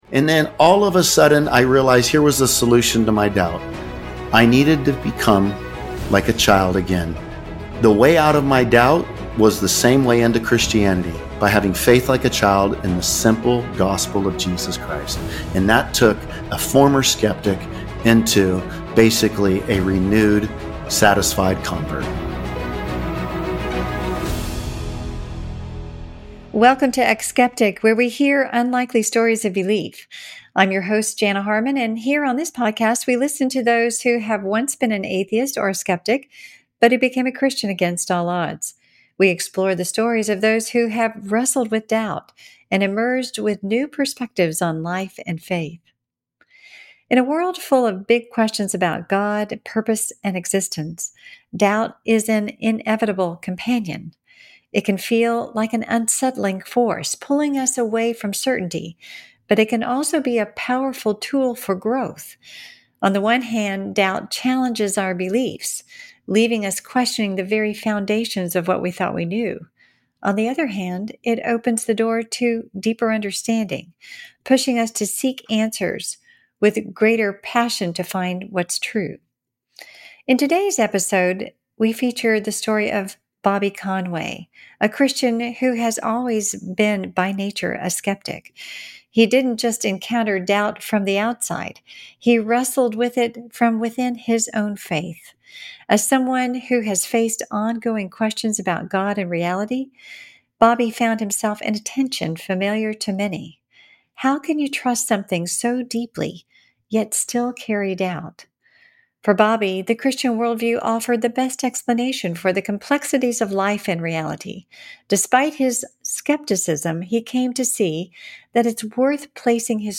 eX-skeptic is a story-driven, conversational podcast that helps listeners understand why people dismiss or believe in God and Christianity.